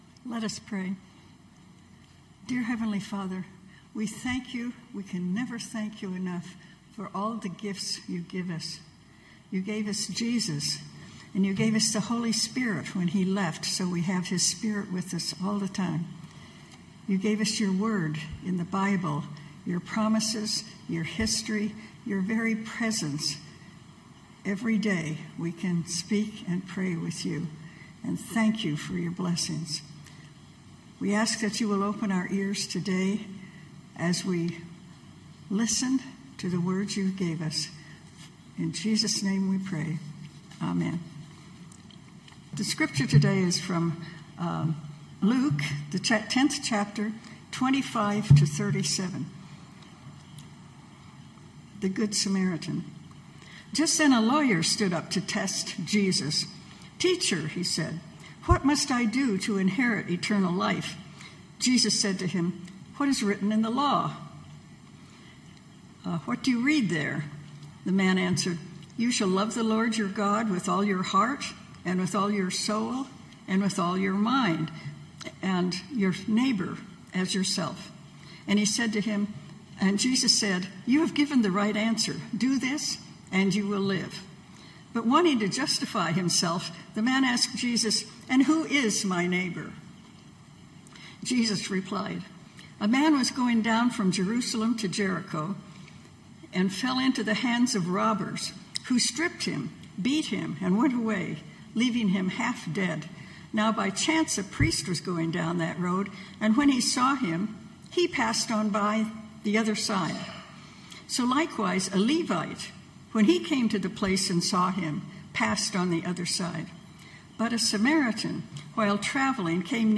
Knox Pasadena Sermons Won't You Be My Neighbor?